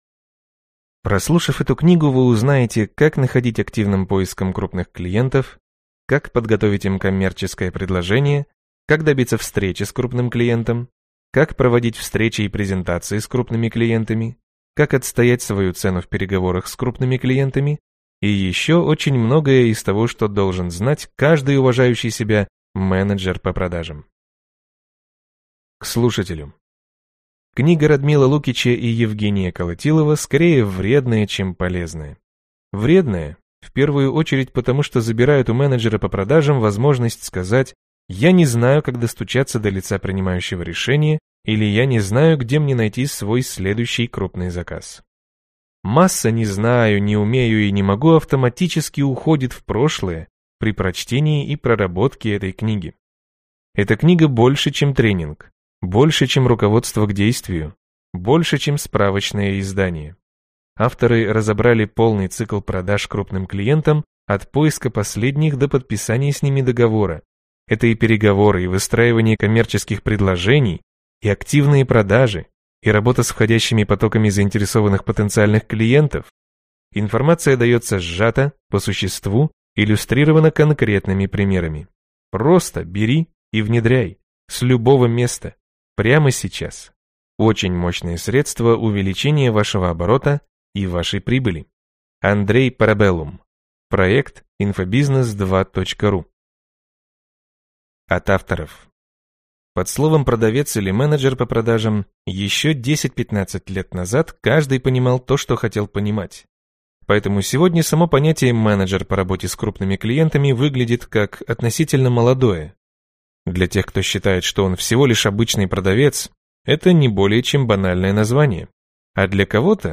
Аудиокнига Техника продаж крупным клиентам. 111 вопросов и ответов | Библиотека аудиокниг
Прослушать и бесплатно скачать фрагмент аудиокниги